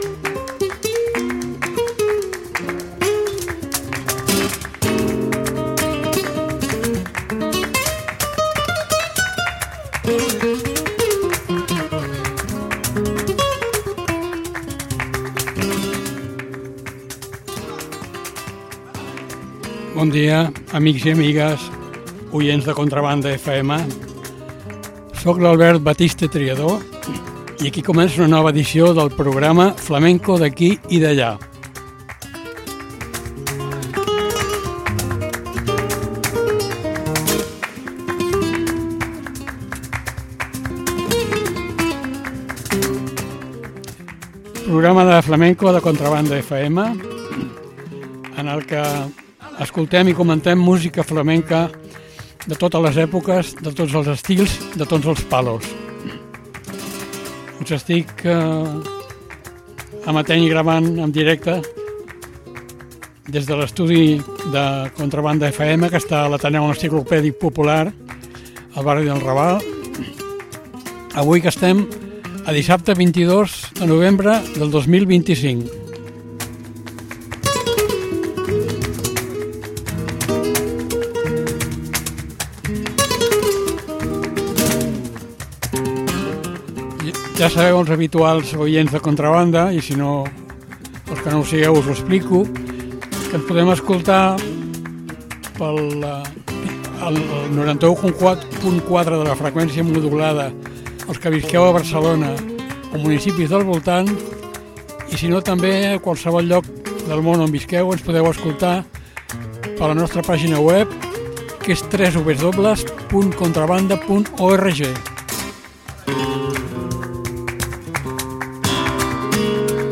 Garrotín. Bulerías. Rumba.